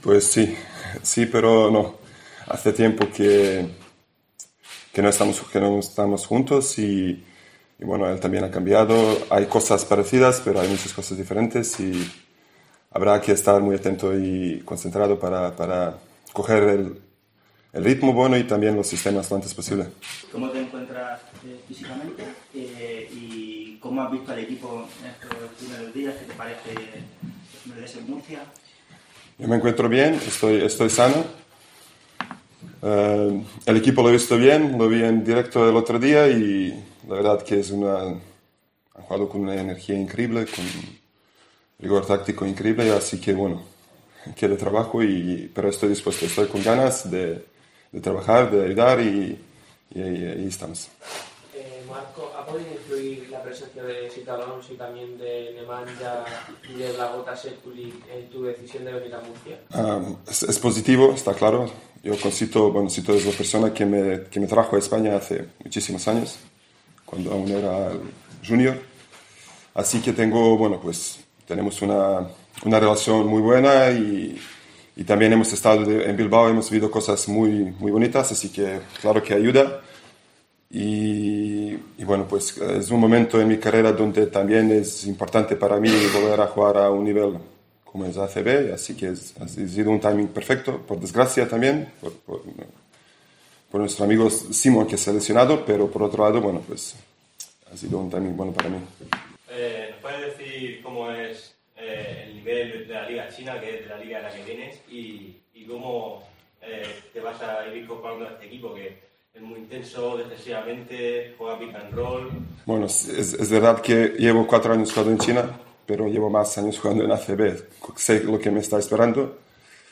El pívot montenegrino asegura en su presentación que "voy a ayudar en todo lo que se me pida. Pronto estaré bien físicamente. Me gusta mucho el equipo"